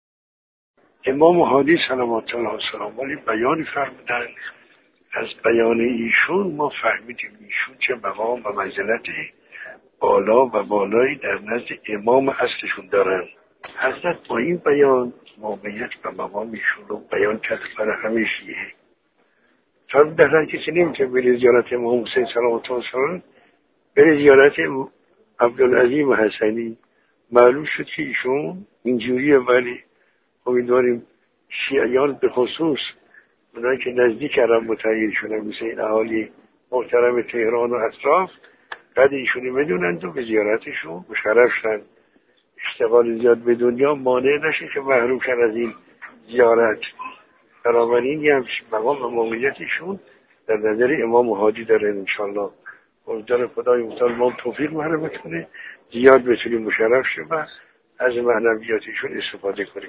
دروس اخلاق